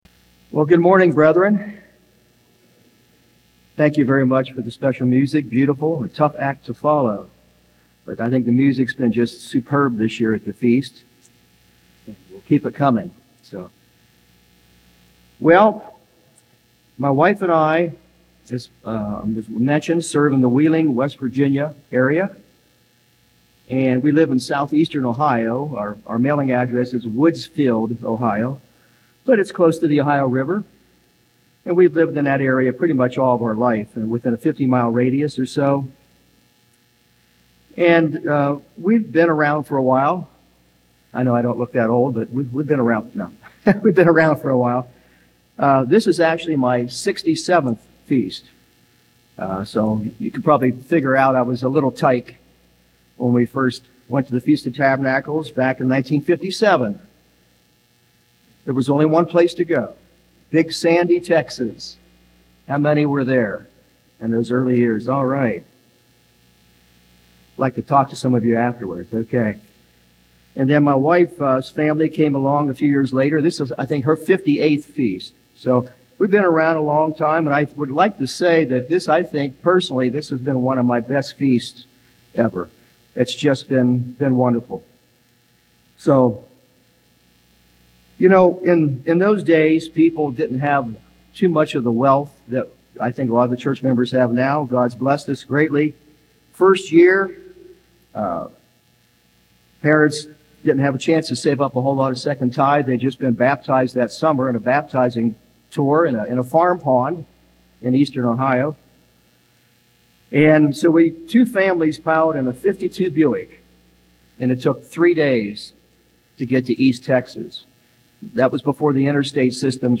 This sermon was given at the Gatlinburg, Tennessee 2023 Feast site.